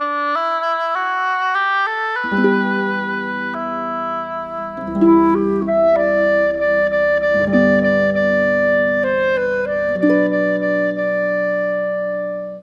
Rock-Pop 09 Harp _ Winds 01.wav